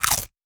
crunch.wav